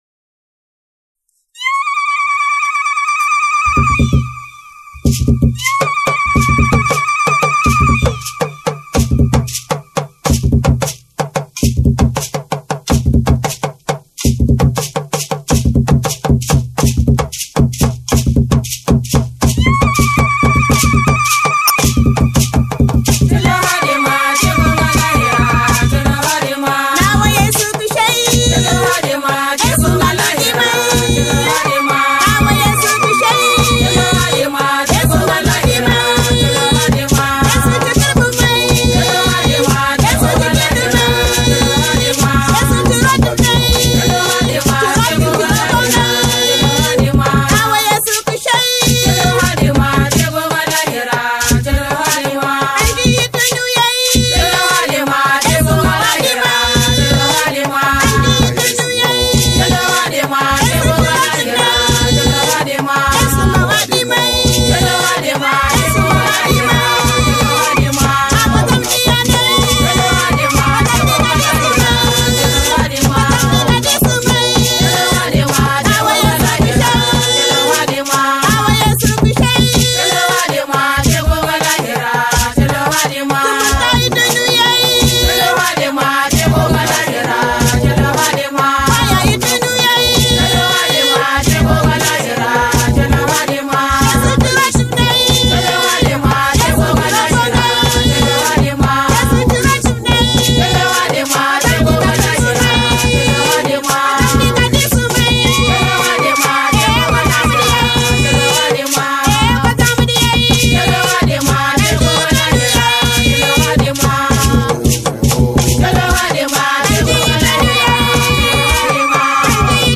chants religieux | Langue jimi
chant de louange